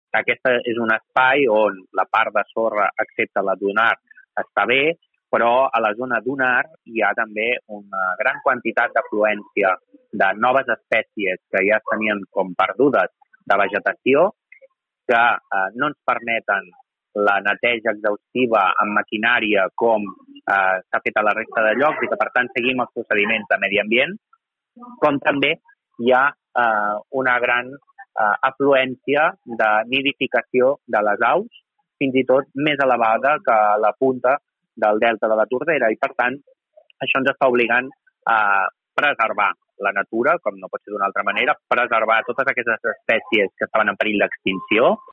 En declaracions a aquesta emissora, Jofre Serret, primer tinent d’alcalde de l’Ajuntament de Malgrat de Mar, ha explicat que a la zona de platja més propera al riu els treballs s’estan gestionant amb cura per no afectar la fauna i flora de la zona.